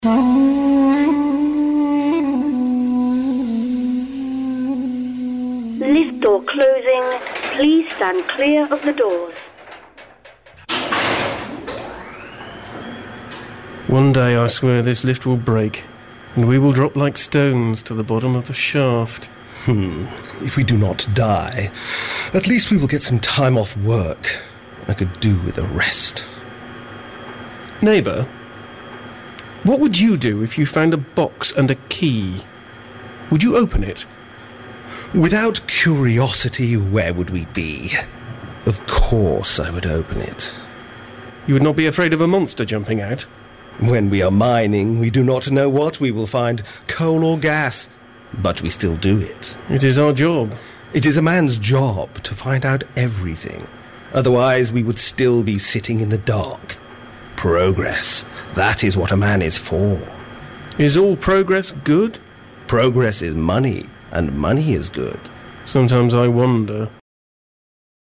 Pandora's Boxes Radio drama always interested me but until Pandora's Boxes I never had a chance to try my hand at it.